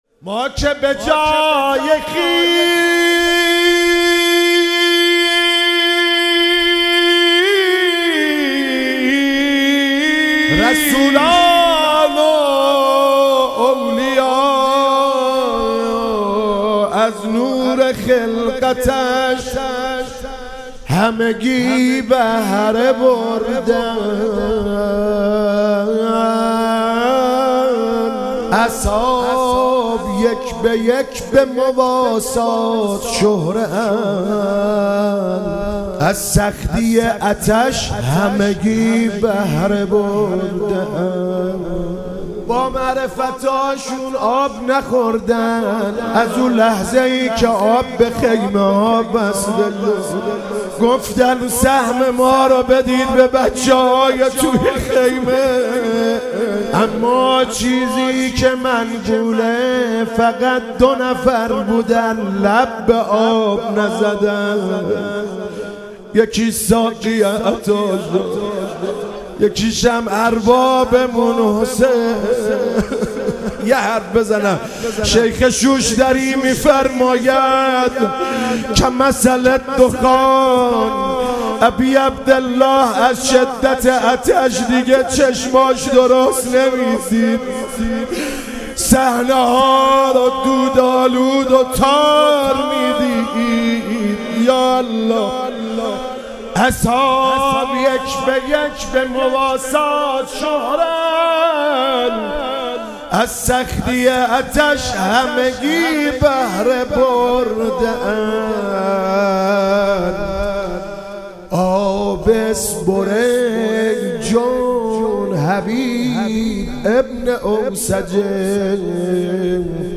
موکب الشهدا ساوجبلاغ